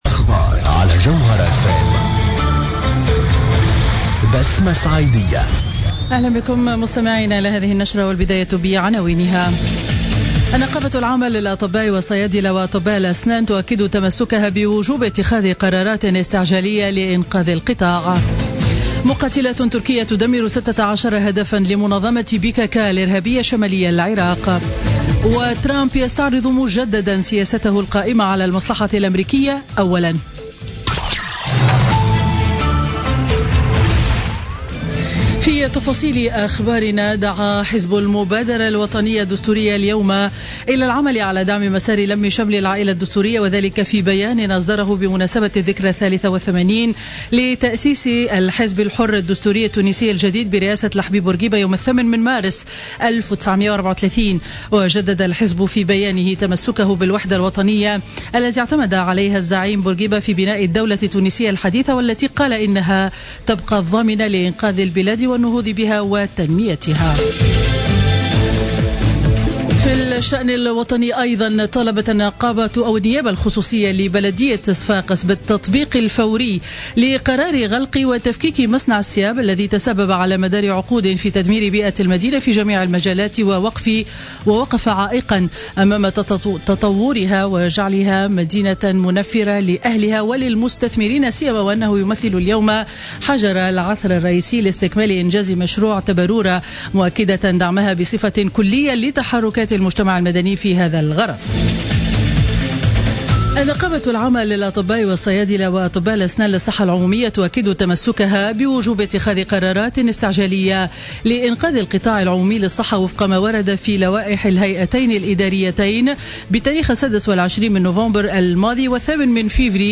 نشرة أخبار منتصف النهار ليوم الأربعاء 1 مارس 2017